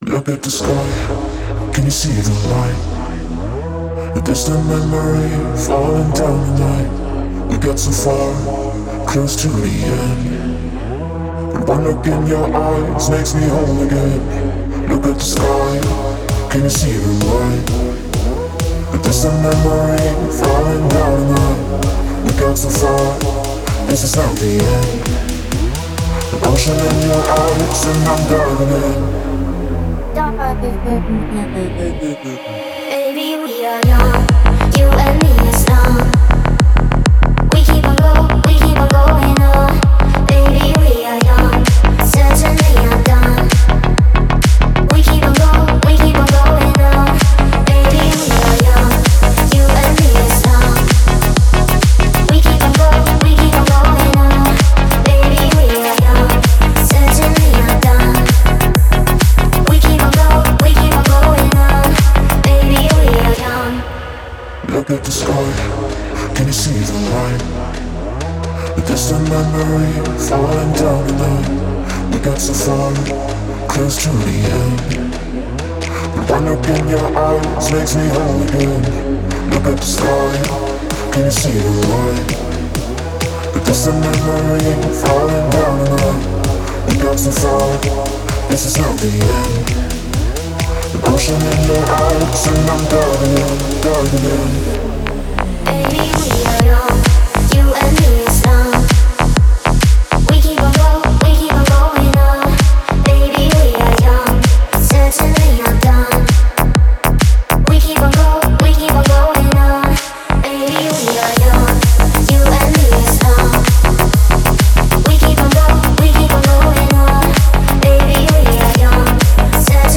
это энергичная песня в жанре поп-рок